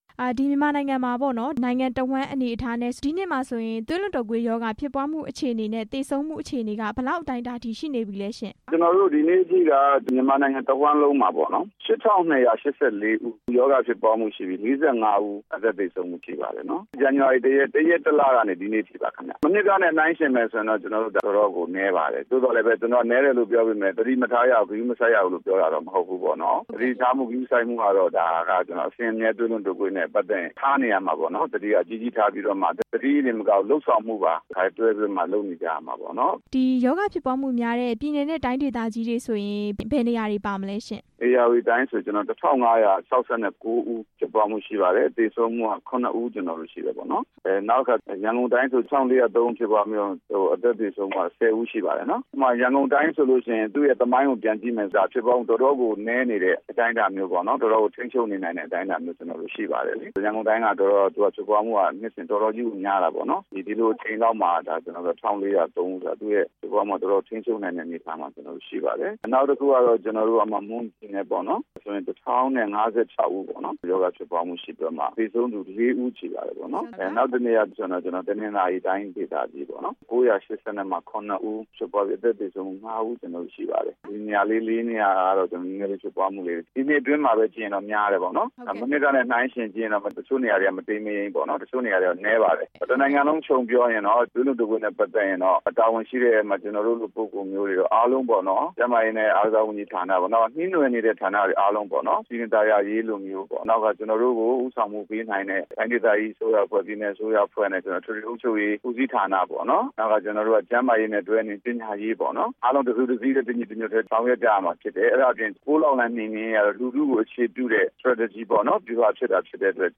သွေးလွန်တုတ်ကွေးရောဂါအခြေအနေ မေးမြန်းချက်